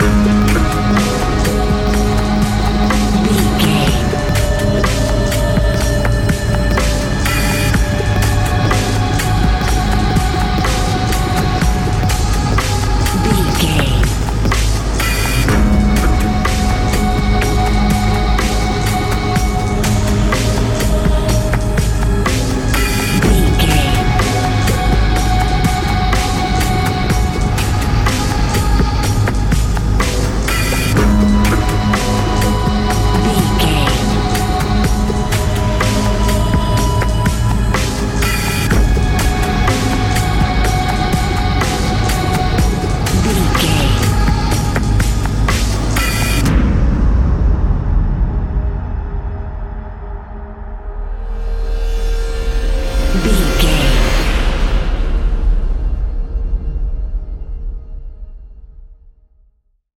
Category: Music